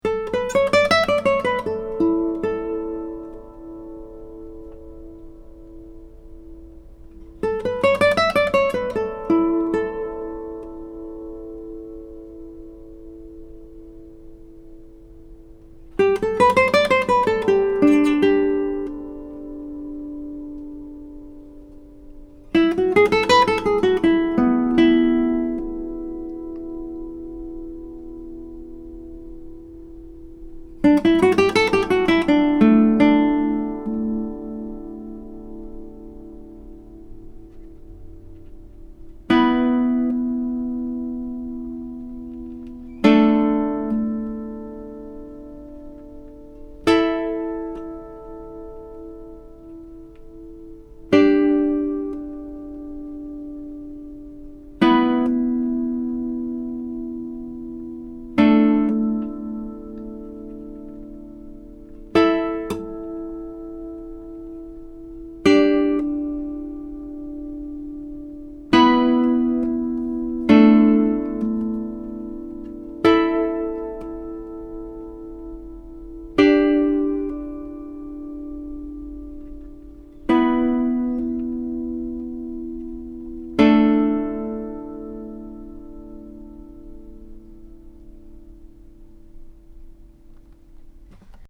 The guitar has amazing sympathetic resonance and sustain, as well as good power and projection, beautiful bass responce, a very even response across the registers. These MP3 files have no compression, EQ or reverb -- just straight signal, tracked through a Wunder CM7GT multi-pattern tube mic, into a Presonus ADL 600 preamp into a Rosetta 200 A/D converter.
2 | Resonance Test (Stopped scales / chords showing sympathetic resonance)